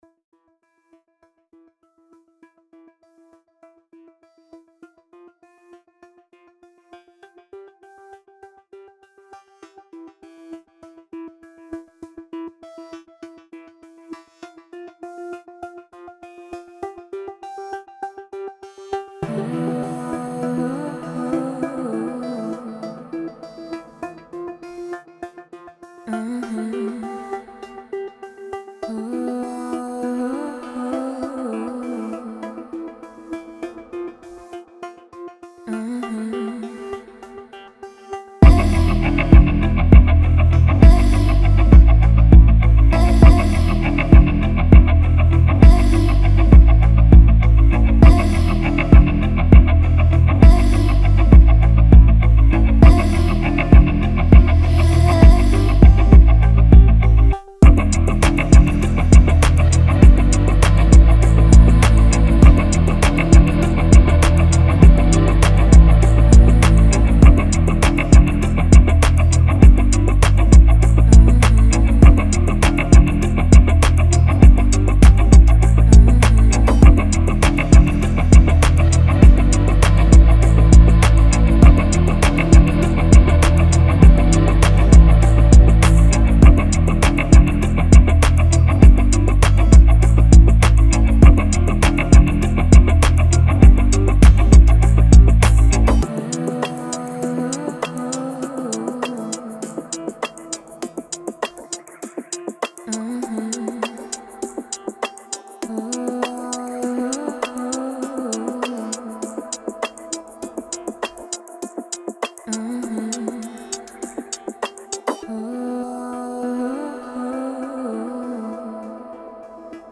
Trip Hop